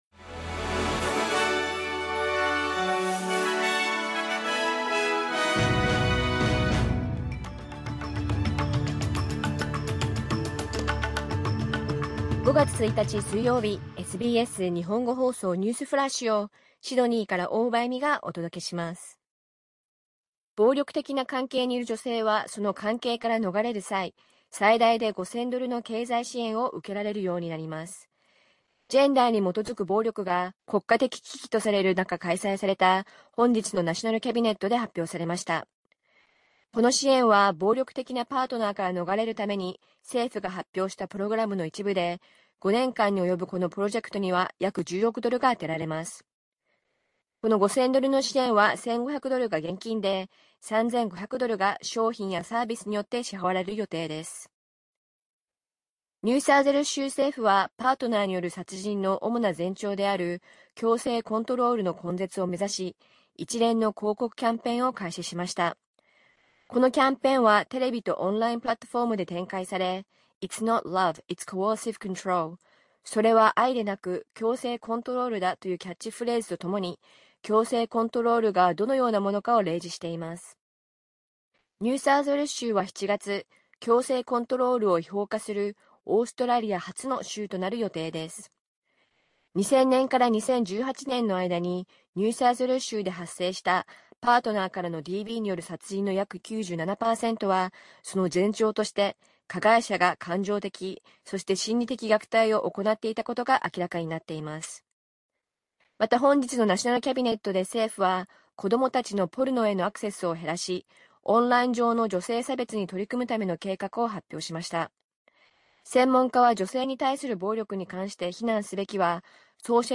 SBS日本語放送ニュースフラッシュ 5月1日水曜日